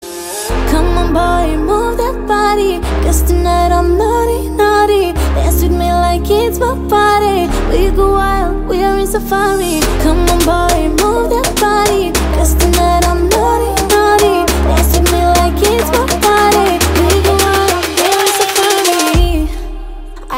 Categoría Electrónica